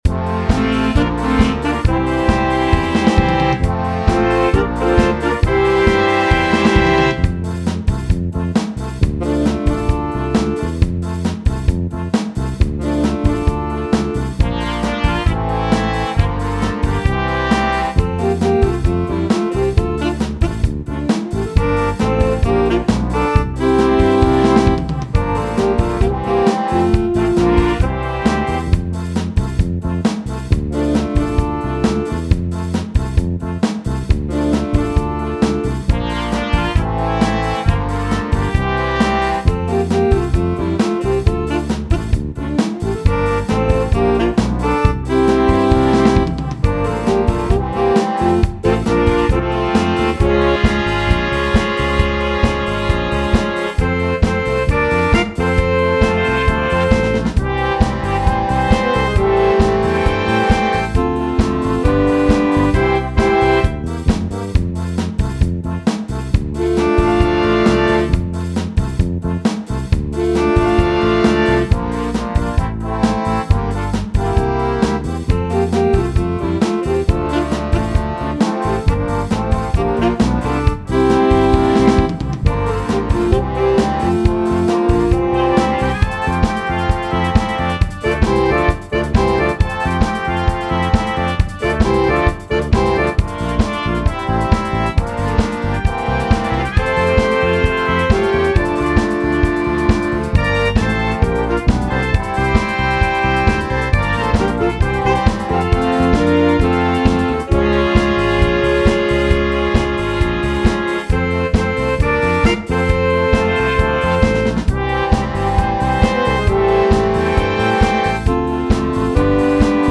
Dychová hudba